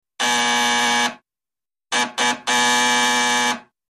Door Buzzer 1; Electric Buzzes; One Long Buzz, Followed By Two Short Buzzes, Into Another Long Buzz. Sharp Brassy-like Attack with Out Any Sustain. Close Perspective.